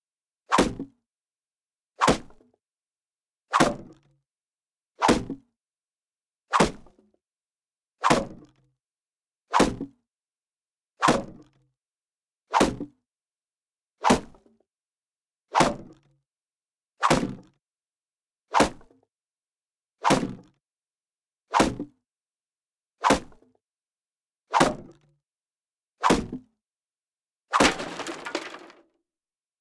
Wooden Spear On Wooden Wall